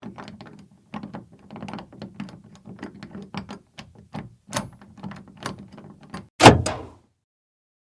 Slim Jim Unlock Car
SFX
Slim Jim Unlock Car.mp3